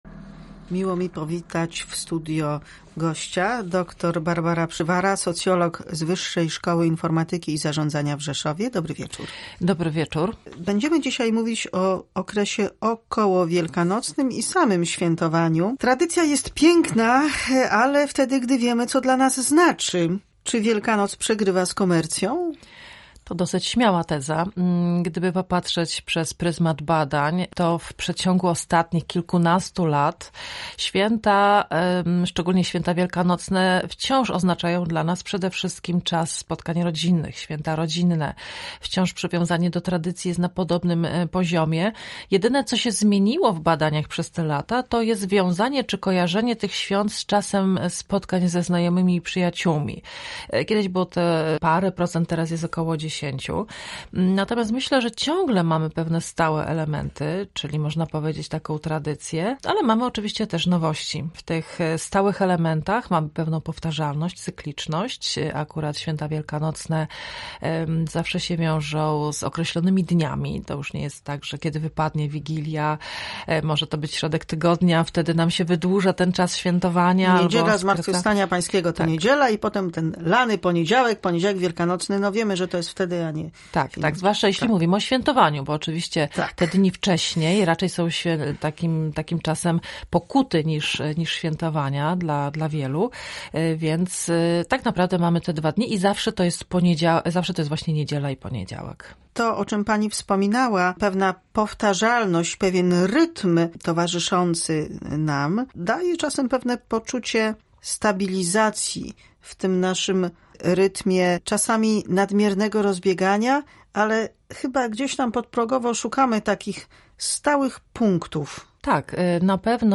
Zjawisko komentuje gość audycji Pisma i znaki